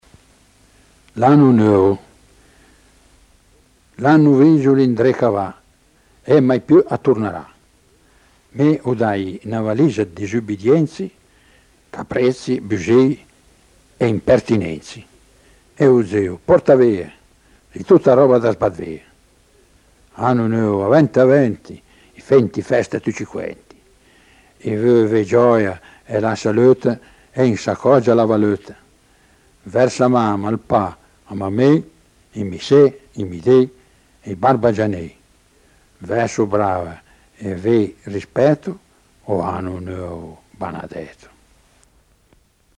scioglilingua